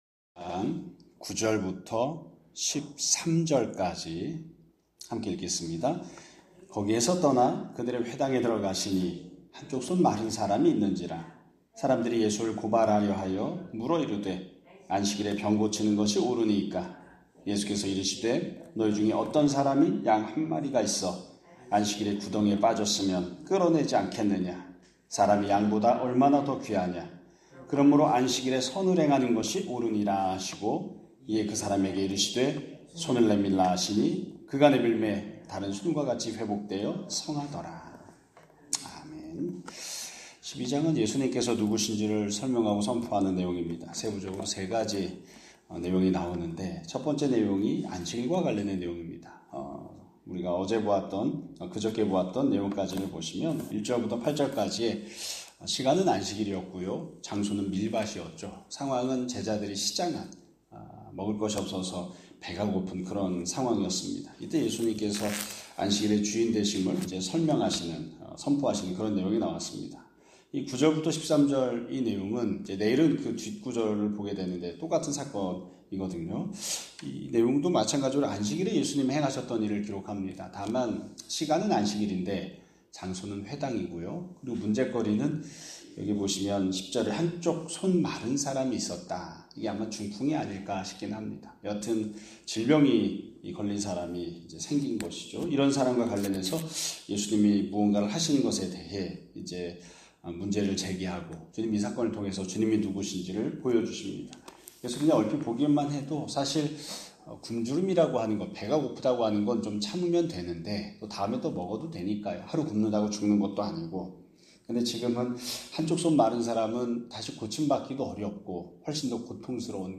2025년 9월 10일 (수요일) <아침예배> 설교입니다.